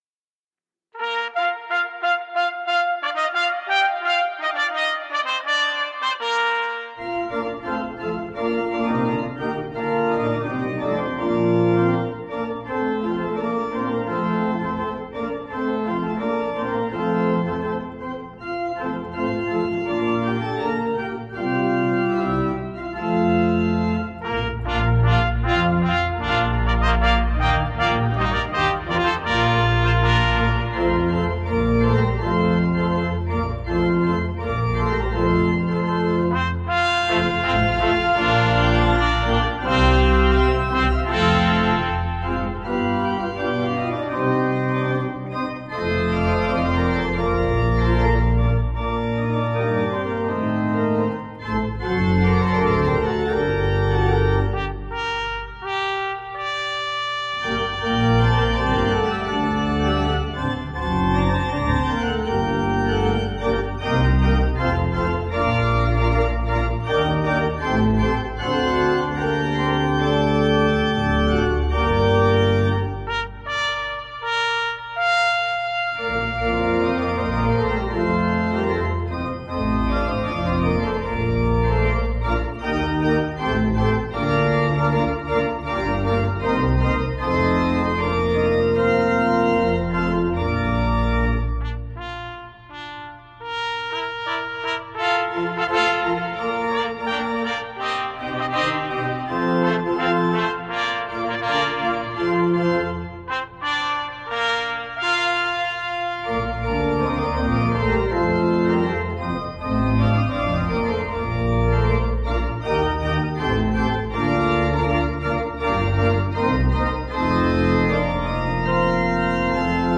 In the midst of it all, resurrection comes, speaking hope into a world of anxiety and death. Recorded in our beautiful Sanctuary, worship includes the joyful music of the day, with festive trumpets and timpani.